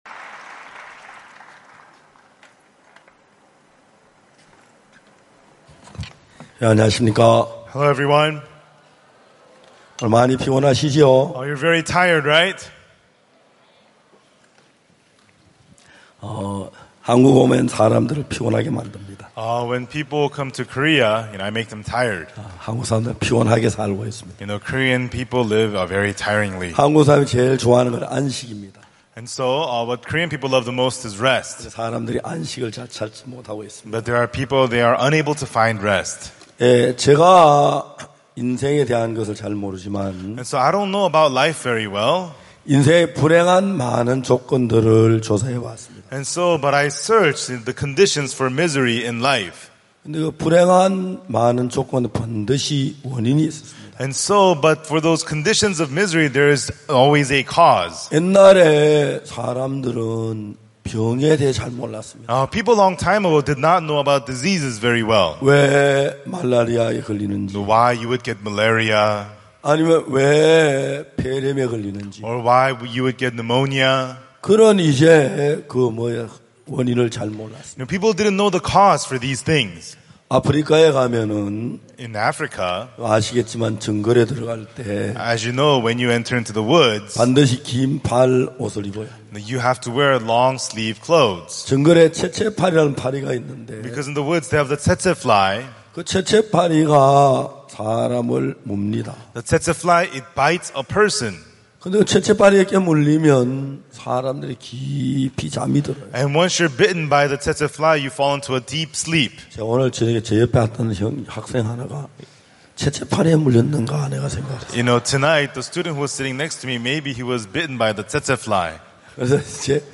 IYF 월드캠프의 주요 프로그램인 마인드 강연. 진정한 삶의 의미를 찾지 못하고 스스로에게 갇혀 방황하는 청소년들에게 어디에서도 배울 수 없는 마음의 세계, 그리고 다른 사람들과 마음을 나누는 방법을 가르친다.